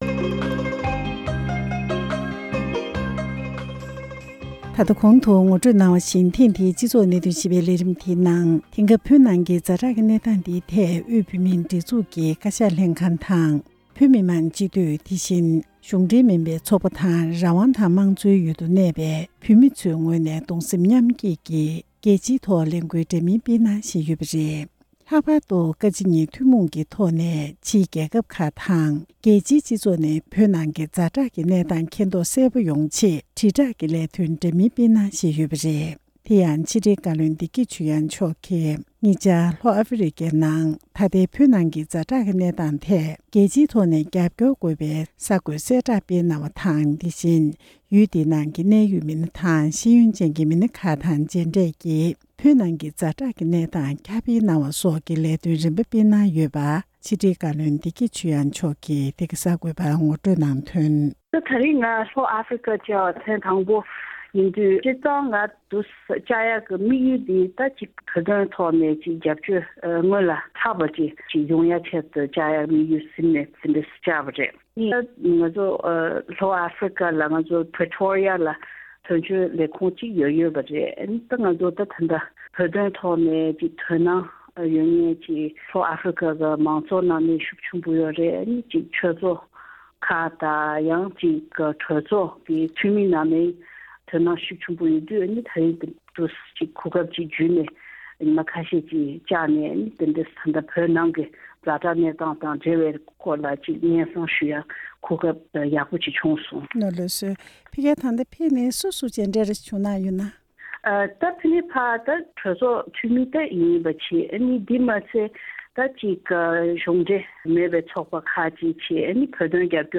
བོད་ཀྱི་ད་ལྟའི་ཛ་དྲག་གནས་སྟངས་སྐོར་བོད་མི་མང་སྤྱི་འཐུས་ཚོགས་གཙོ་དང་། ཕྱི་དྲིལ་བཀའ་བློན་གྱི་དབུས་པའི་གཞུང་འབྲེལ་མ་ཡིན་པའི་ཚོགས་པ་ཁག་ཅིག་གི་གནས་ཡོད་མི་སྣར་གླེང་མོལ་ཞུས་པའི་ལས་རིམ།